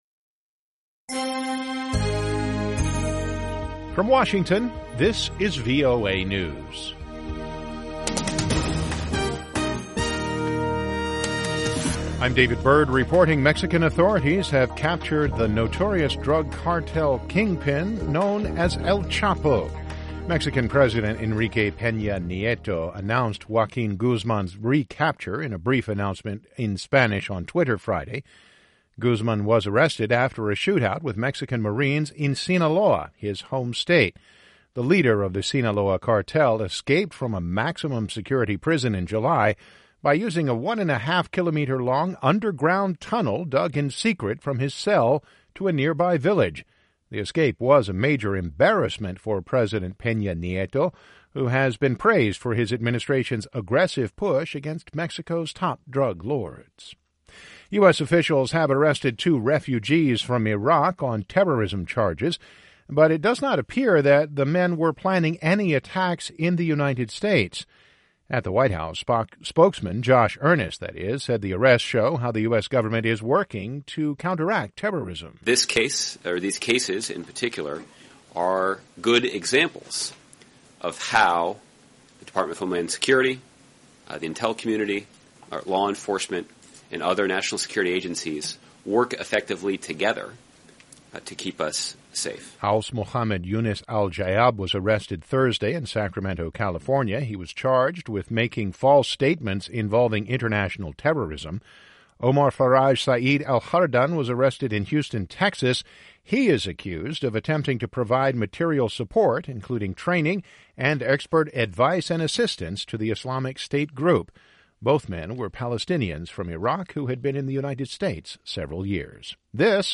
VOA English Newscast: 2200 January 8, 2016